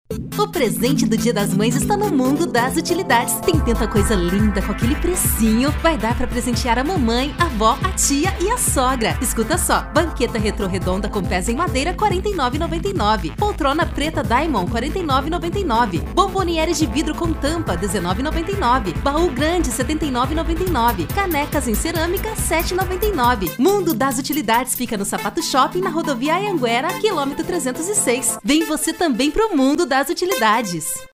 VOZES FEMININAS
Estilos: Padrão